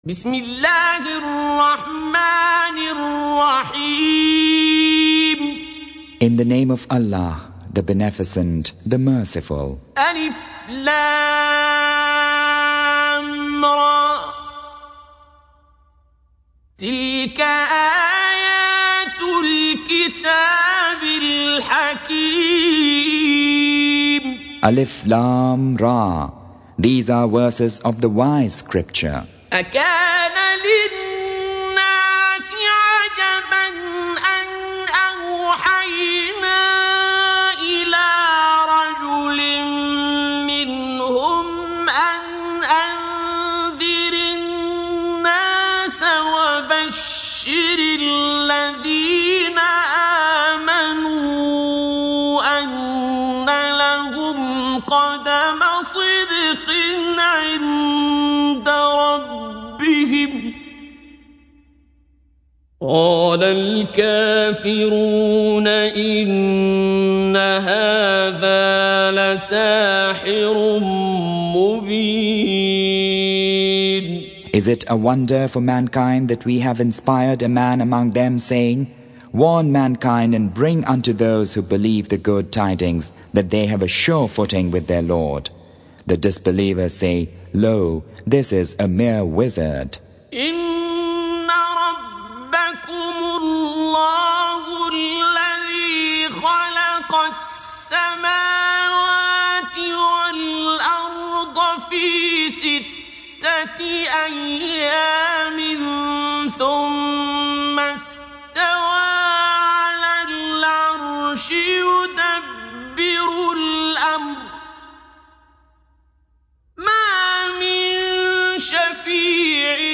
Surah Sequence تتابع السورة Download Surah حمّل السورة Reciting Mutarjamah Translation Audio for 10.